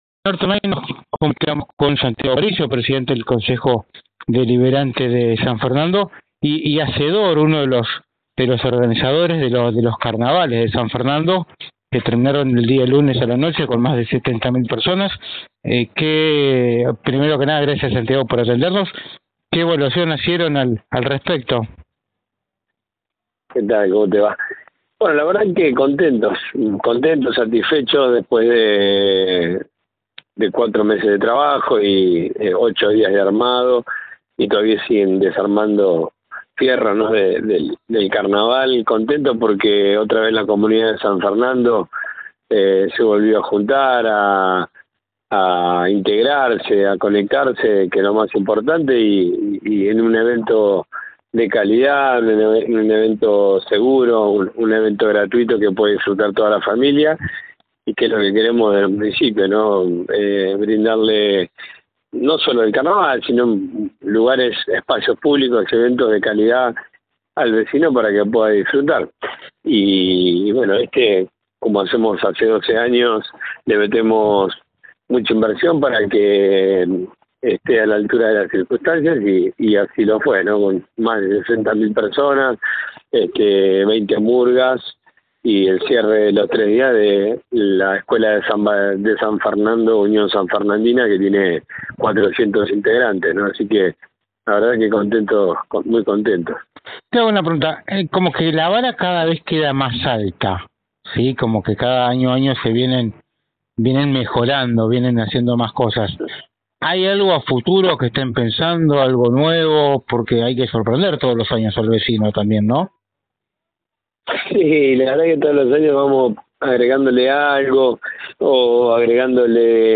El presidente del HCD de San Fernando conversó con NorteOnline y confirmó la creación del espacio festivo en el distrito.